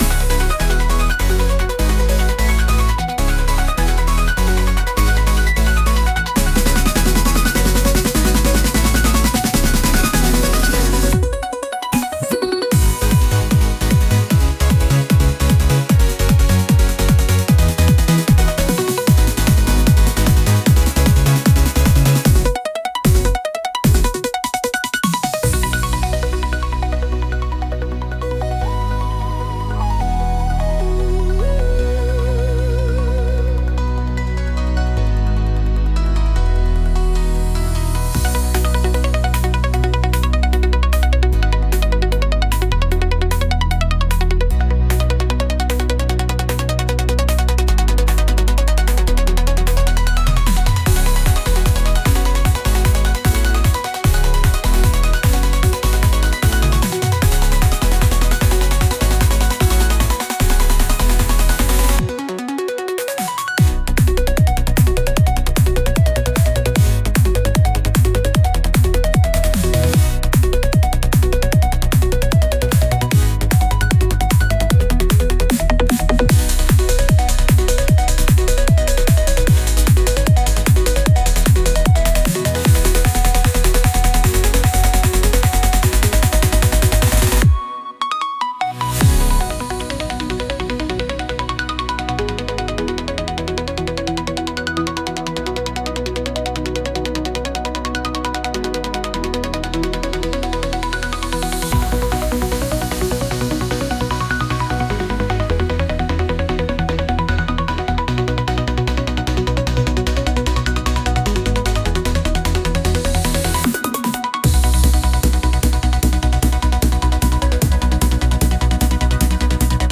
Genre: Hyperpop Mood: Energetic Editor's Choice